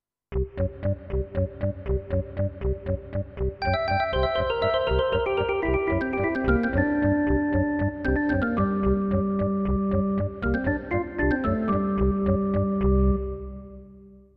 12. I SUONI - GLI STRUMENTI XG - GRUPPO "ORGAN"